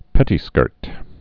(pĕtē-skûrt)